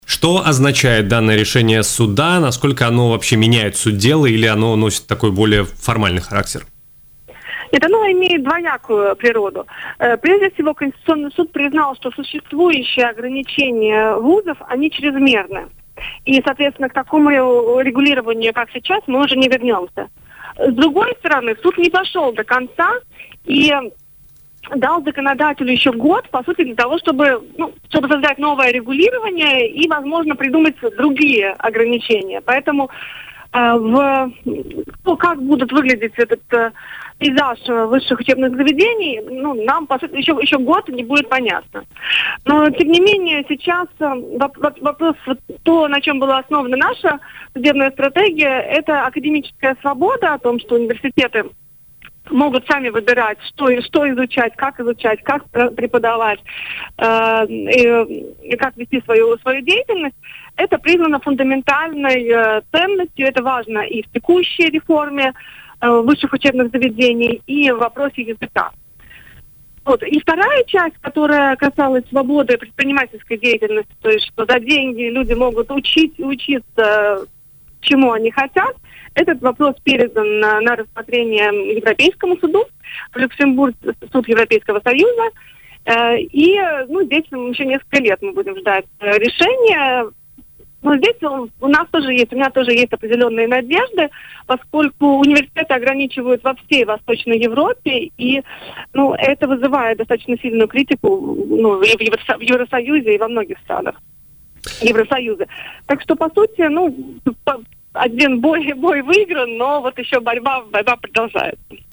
Об этом в эфире радио Baltkom заявила депутат Юрмальской думы, юрист и правозащитник Елизавета Кривцова.